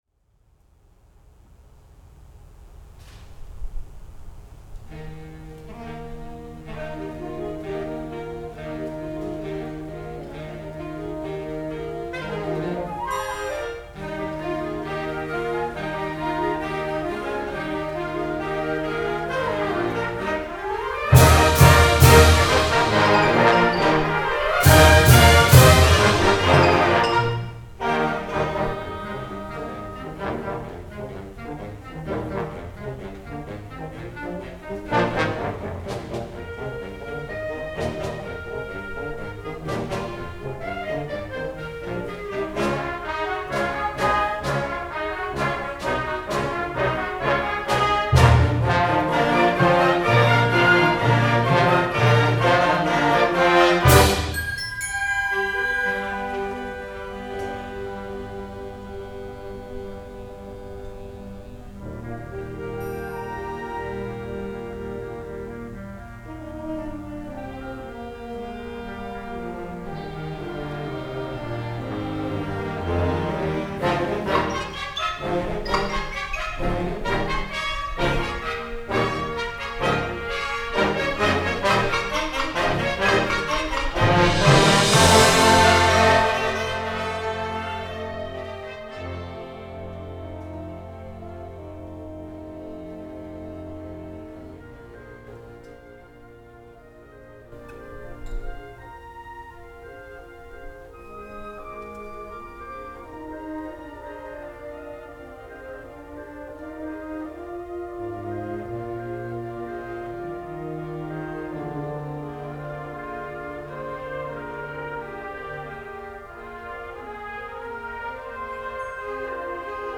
The LBB's winter concert was on Sunday March 6th 2022 at the Lexington High School in Lexington.